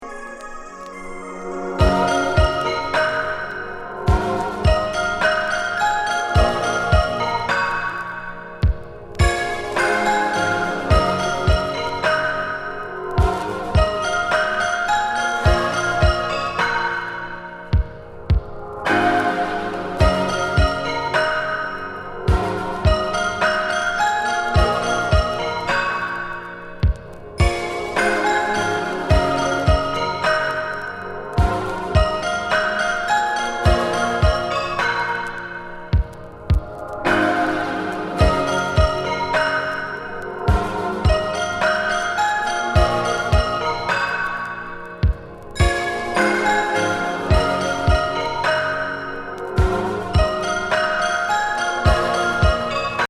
サントラ。牧歌メロディ・オーケストラ・ニューエイジ